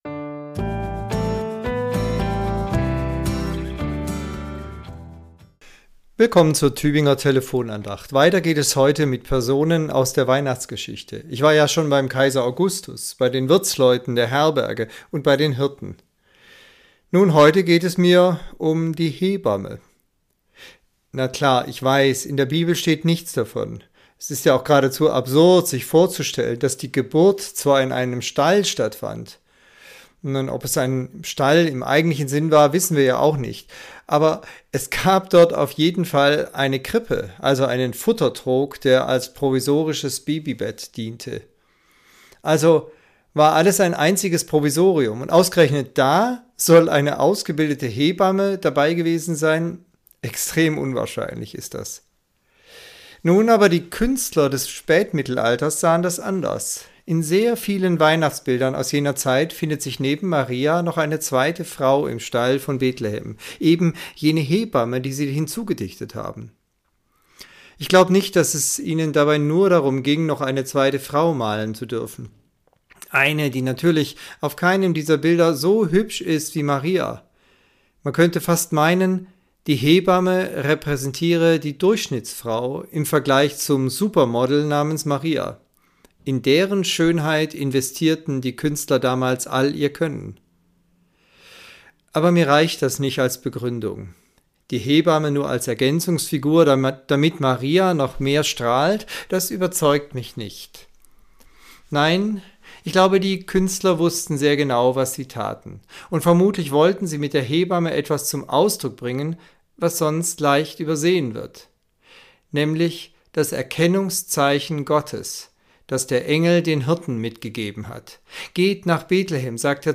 Andacht zur Weihnachtswoche Teil 4